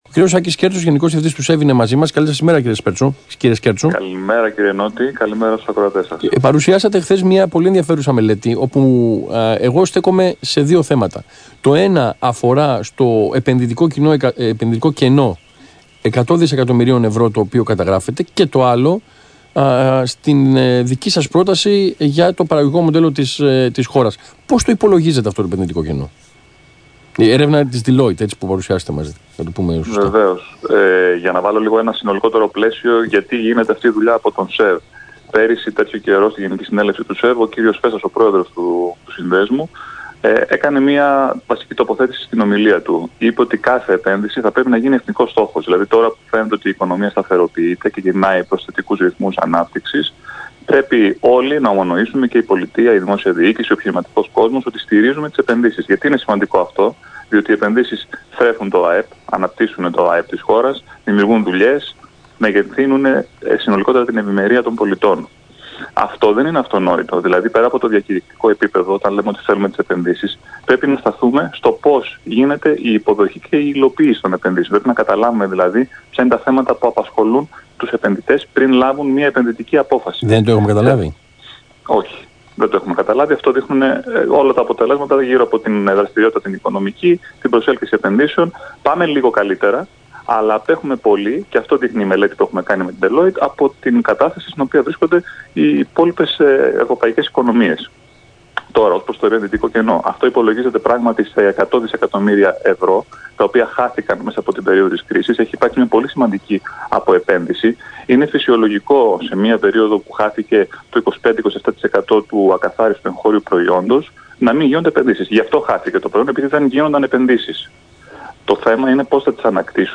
Συνέντευξη του Γενικού Διευθυντή του ΣΕΒ, κ. Άκη Σκέρτσου στον Ρ/Σ ΑΘΗΝΑ 9.84, 19/4/2018